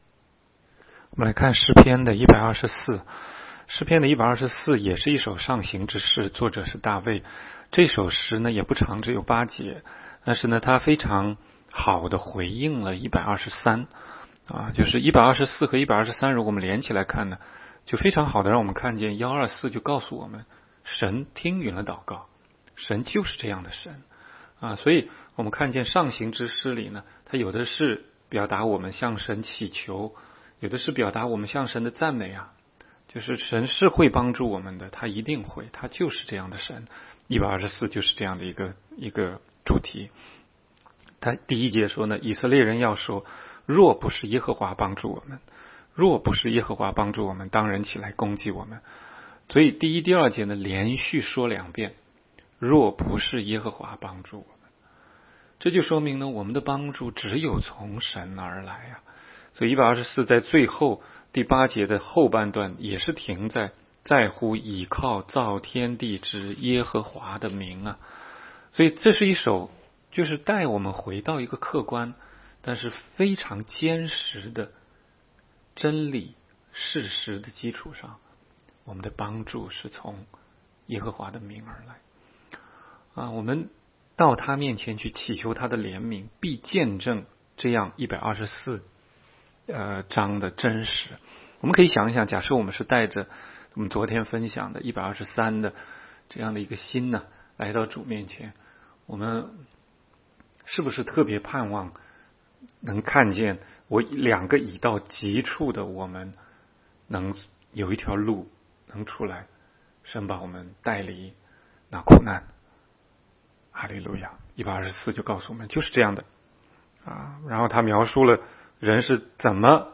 16街讲道录音 - 每日读经 -《 诗篇》124章